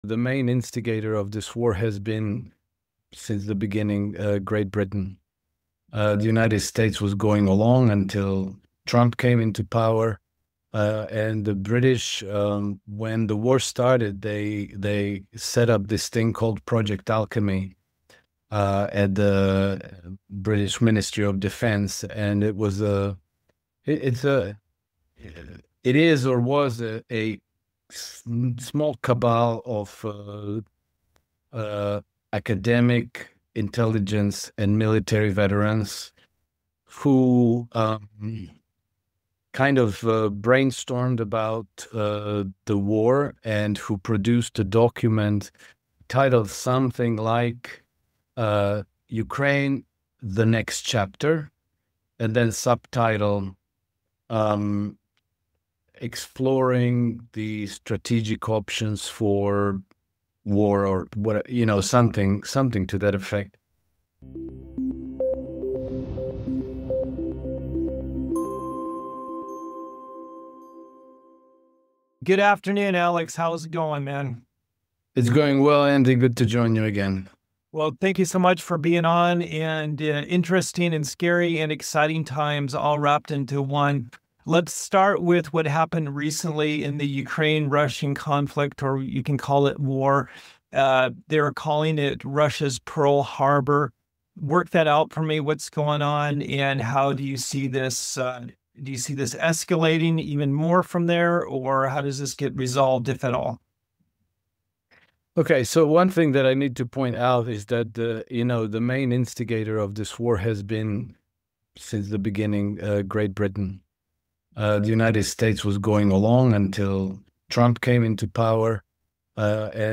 In this intense discussion